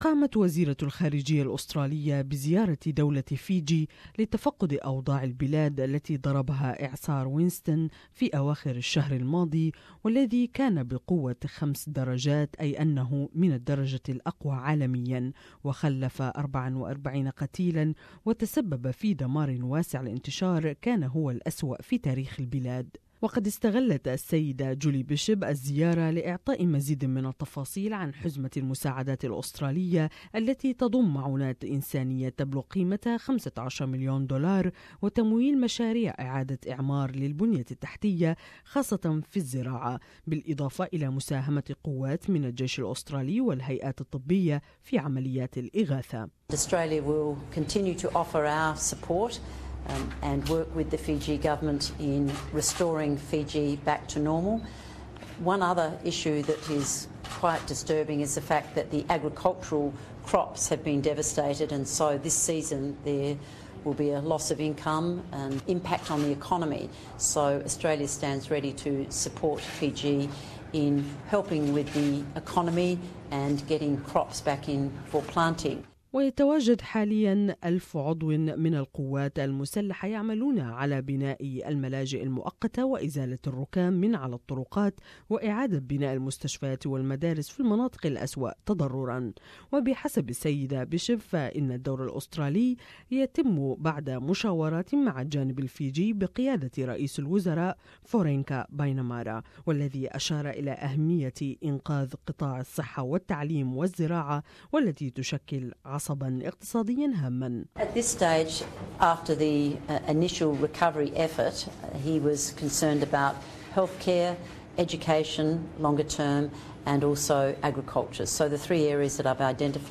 تقرير اخباري عن زيارة رئيسة الوزراء السيدة جولي بيشوب لفيجي و المساعدات الانسانية لذلك البلد عقب الاعصار المدمر الذي ضربه الشهر الماضي